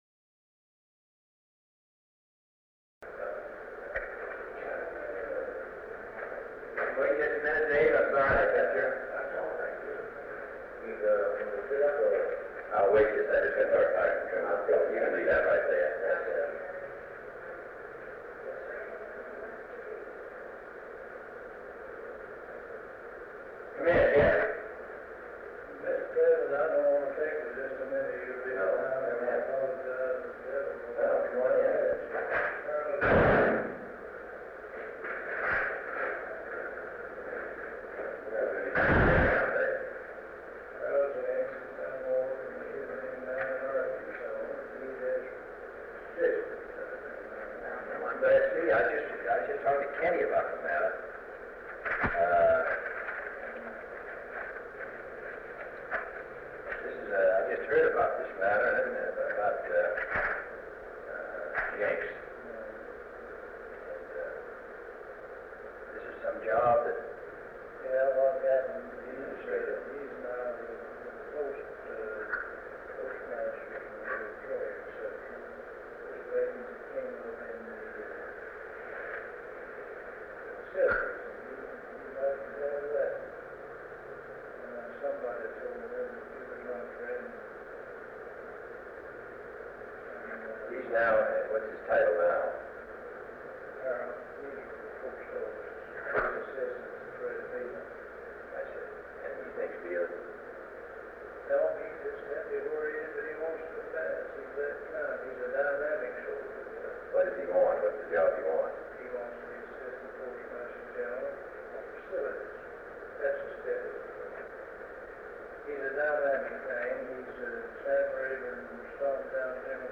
Sound recording of a meeting held on July 29, 1963, between President John F. Kennedy and Representative James Trimble of Arkansas. They briefly discuss the U.S. Post Office Postmaster General position.